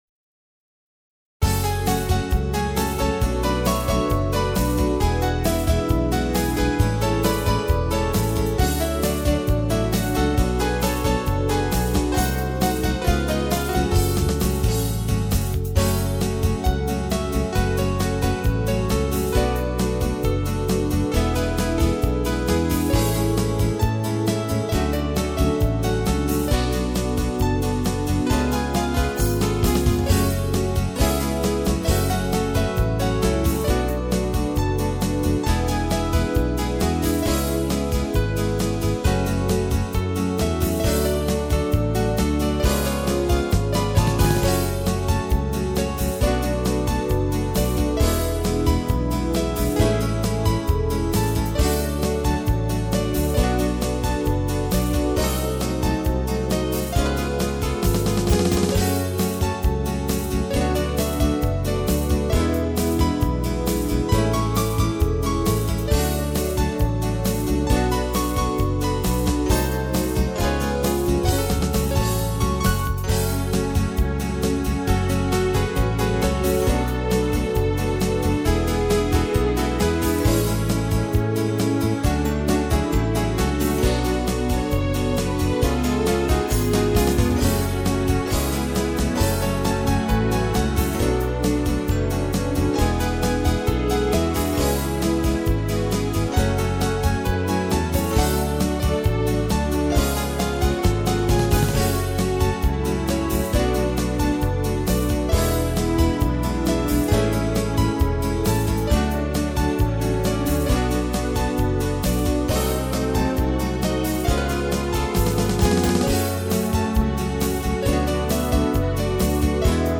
Альтернативная (2891)
минус